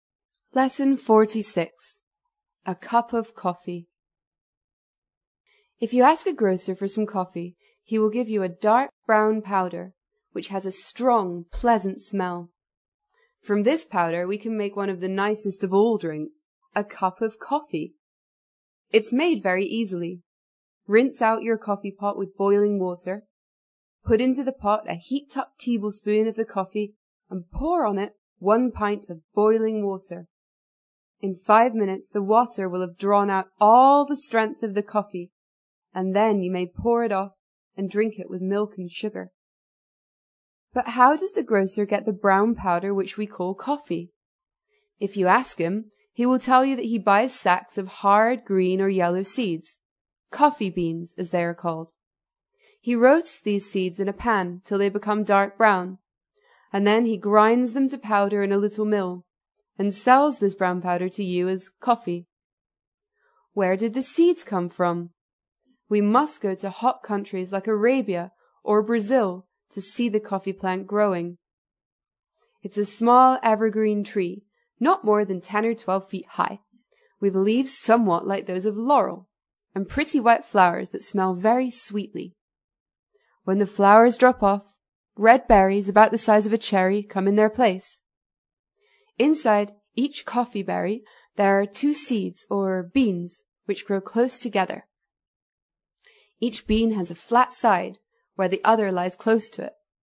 在线英语听力室英国学生科学读本 第46期: 一杯咖啡(1)的听力文件下载,《英国学生科学读本》讲述大自然中的动物、植物等广博的科学知识，犹如一部万物简史。在线英语听力室提供配套英文朗读与双语字幕，帮助读者全面提升英语阅读水平。